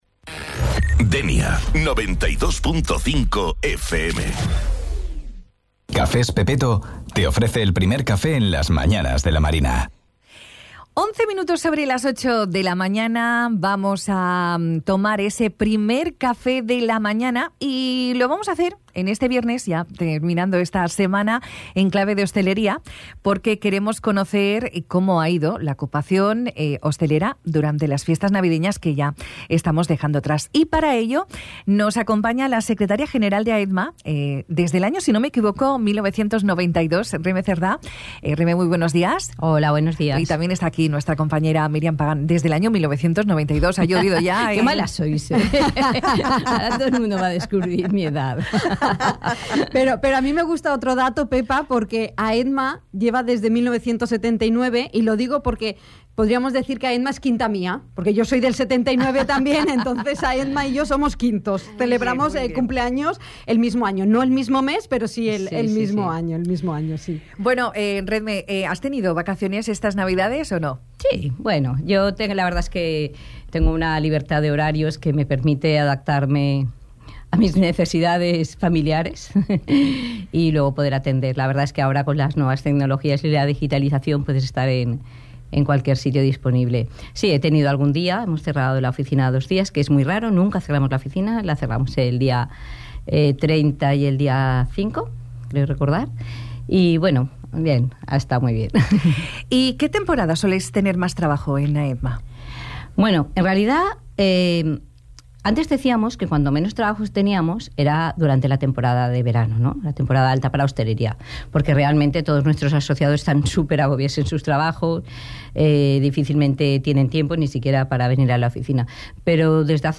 Entrevista-Aehtma.mp3